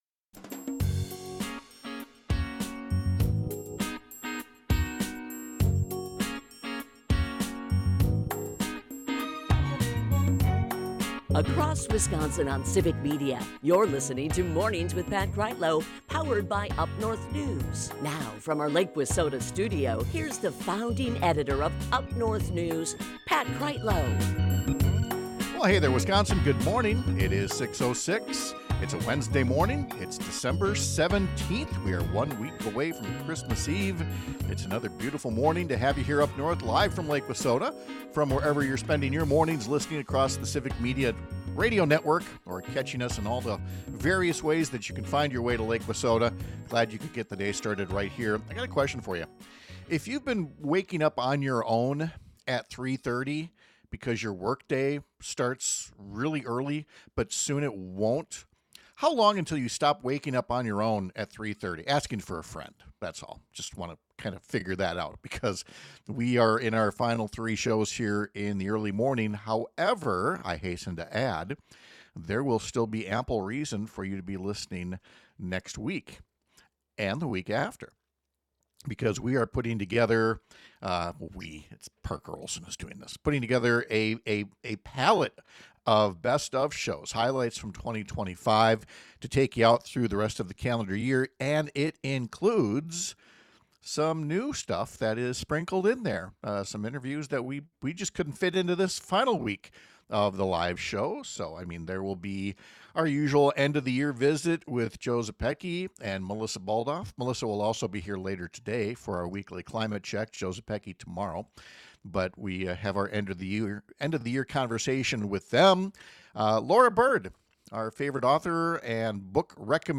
Mornings with Pat Kreitlow is powered by UpNorthNews, and it airs on several stations across the Civic Media radio network, Monday through Friday from 6-9 am.